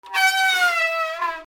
自転車 ブレーキ
/ E｜乗り物 / E-45 ｜自転車
『キィー』 雨の日